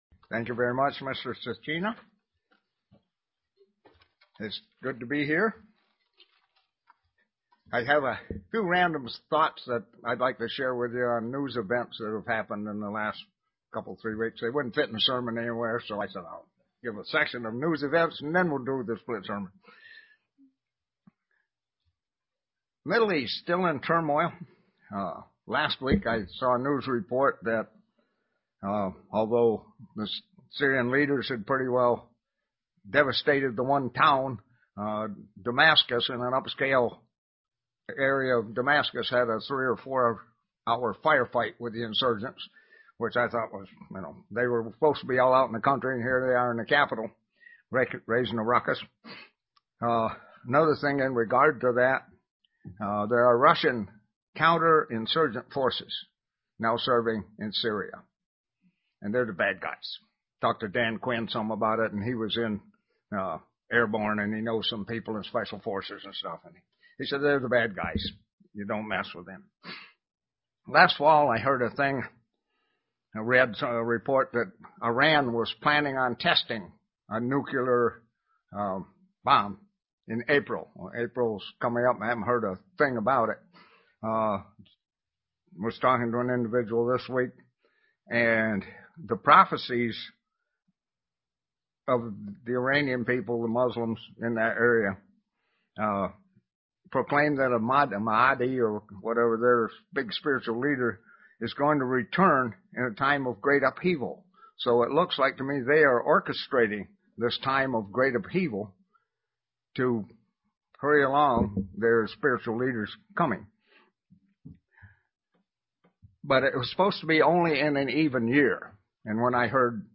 Print The Life of Abraham and what it means to us today UCG Sermon Studying the bible?
Given in Elmira, NY